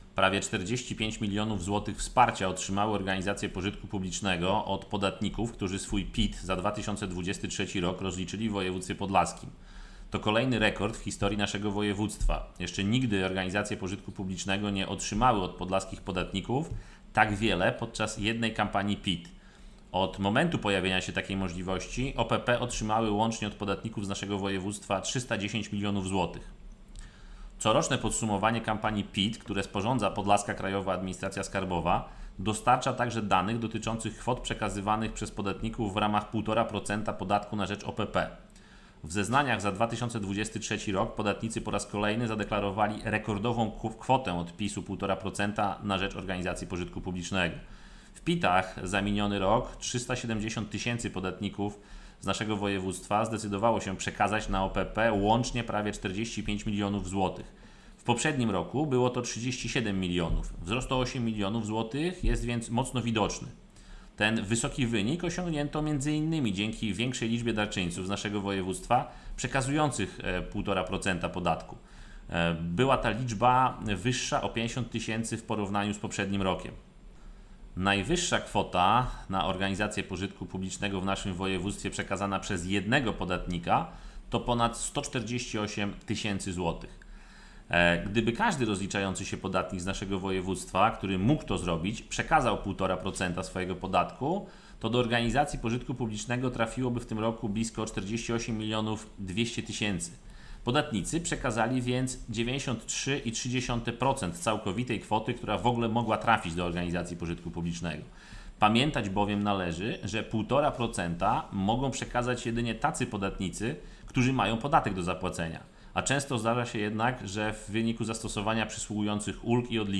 Posłuchaj wypowiedzi dotyczącej przekazania kwot podatku za 2023 r. przez podlaskich podatników organizacjom pożytku publicznego